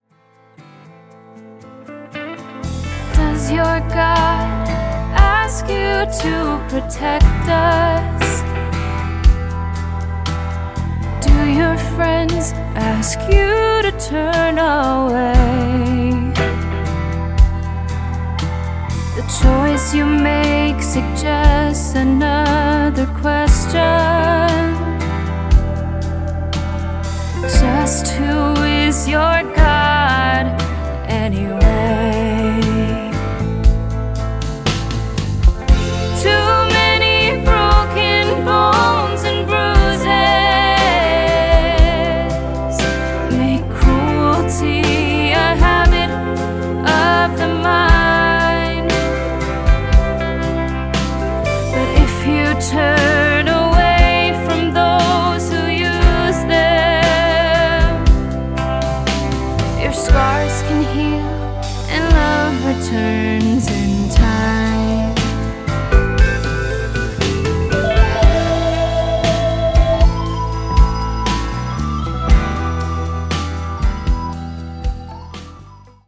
a wonderful young singer
The album includes a haunting title track